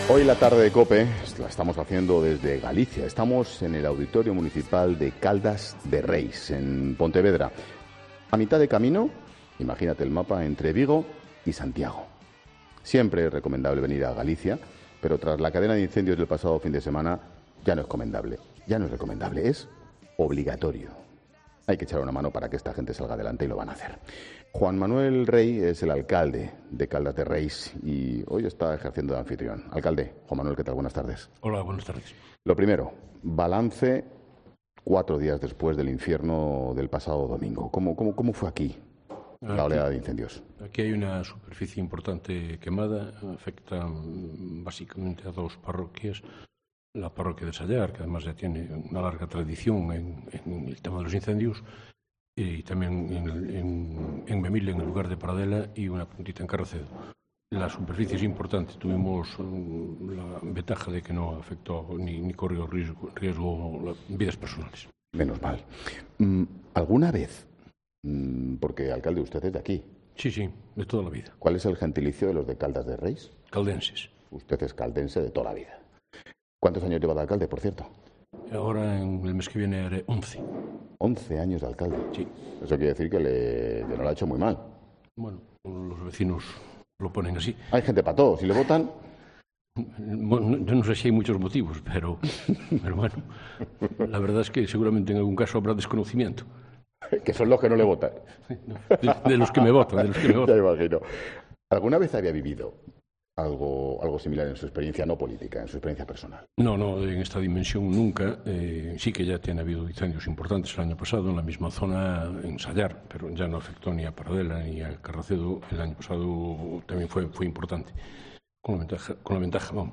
Juan Manuel Rey, alcalde de Caldas de Reis (Pontevedra), ha contado en 'La Tarde' la importancia tanto del Camino de Santiago como del termalismo...